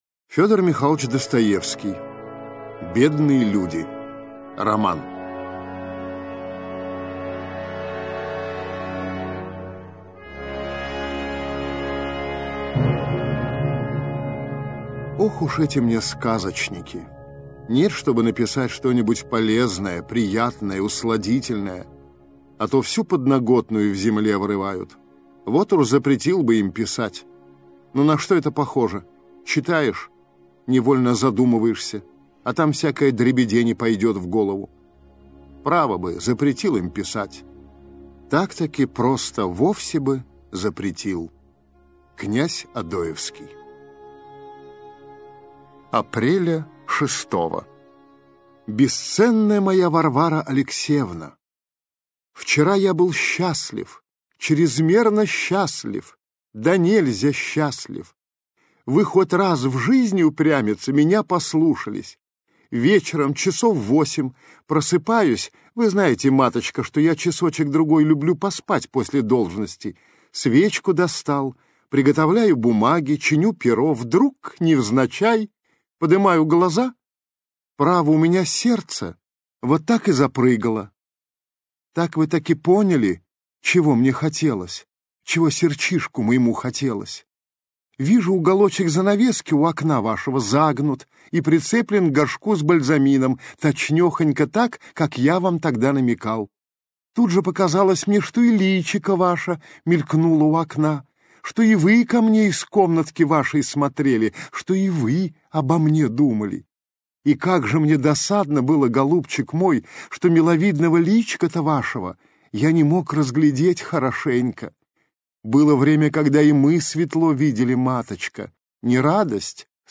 Аудиокнига Бедные люди - купить, скачать и слушать онлайн | КнигоПоиск